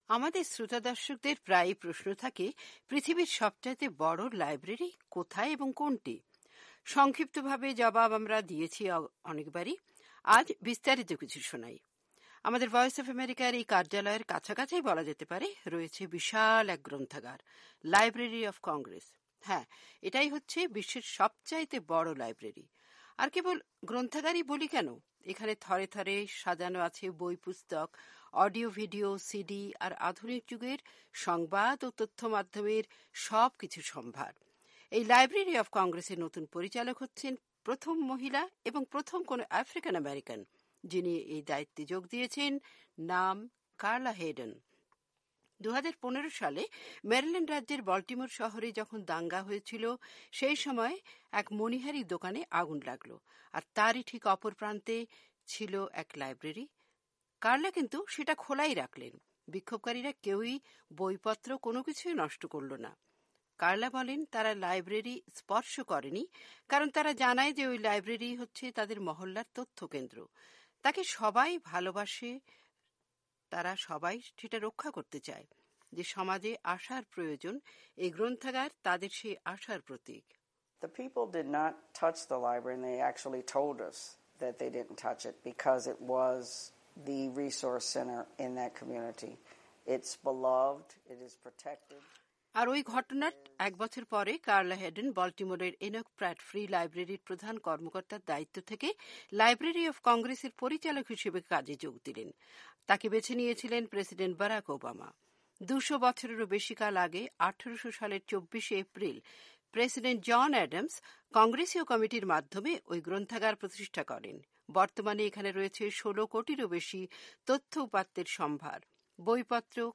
আমাদের শ্রোতাদর্শকদের প্রায়ই প্রশ্ন থাকে পৃথিবীর সবচাইতে বড় লাইব্রেরী কোথায়?